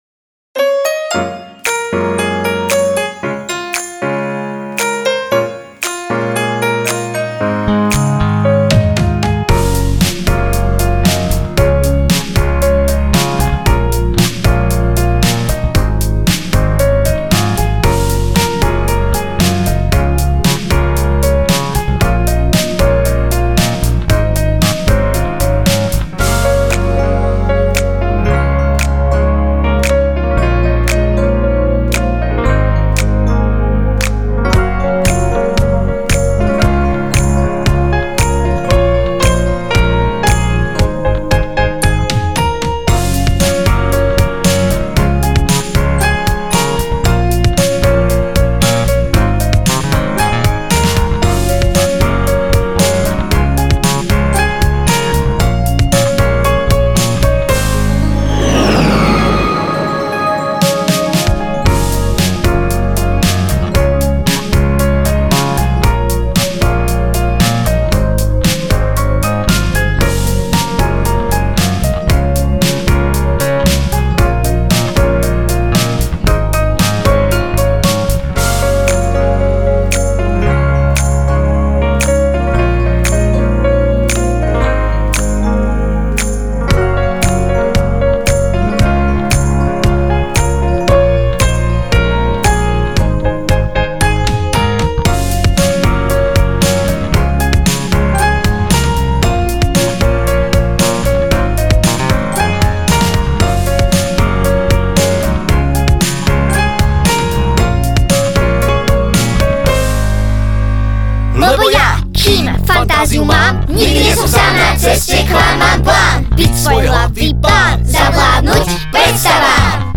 KARAOKE
Karaoke verzia